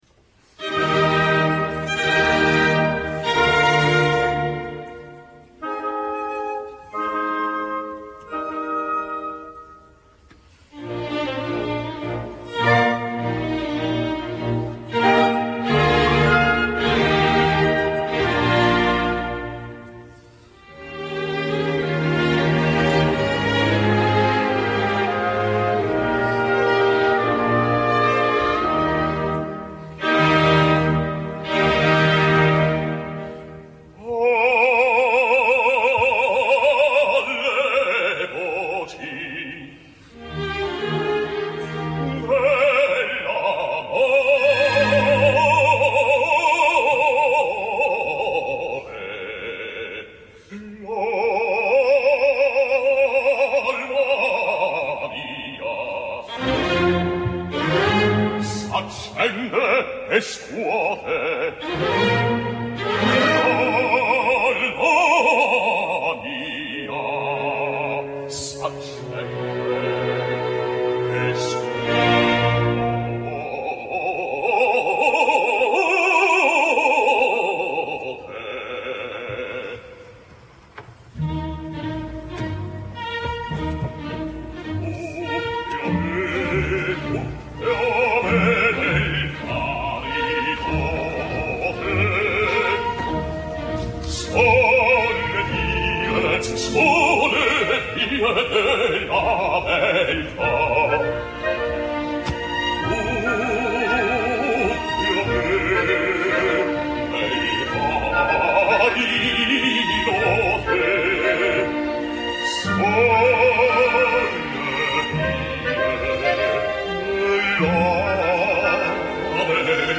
Té solvència i estil rossinià.
Us deixo escoltar l’ària que inicià la segona part i que s’ha incorporat per aquesta edició del ROF 2009.
aria-de-blansac.mp3